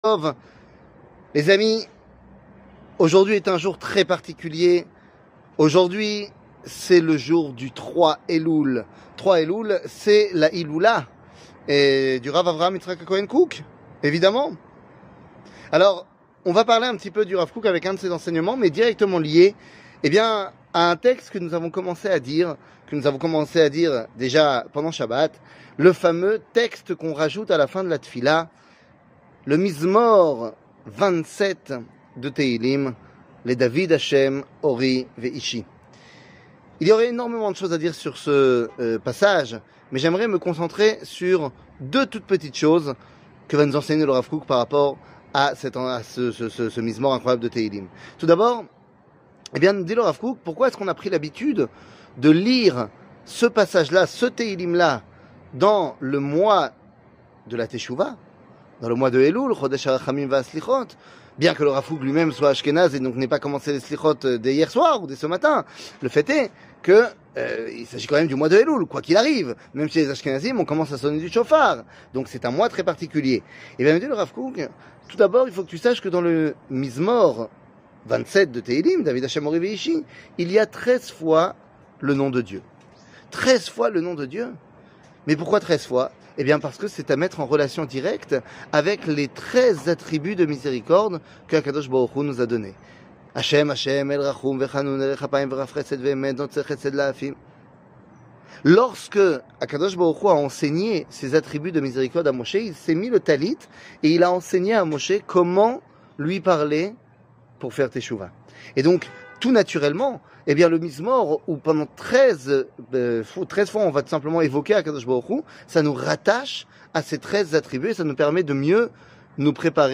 L'espoir du Olam Aba, du monde a venir 00:04:52 L'espoir du Olam Aba, du monde a venir שיעור מ 20 אוגוסט 2023 04MIN הורדה בקובץ אודיו MP3 (4.46 Mo) הורדה בקובץ וידאו MP4 (7.78 Mo) TAGS : שיעורים קצרים